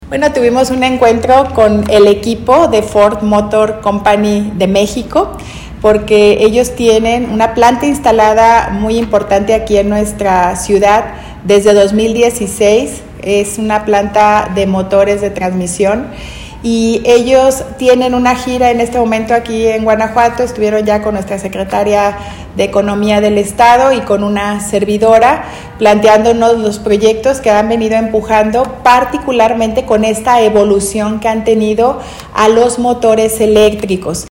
AudioBoletines
Lorena Alfaro García, presidenta municipal deIrapuato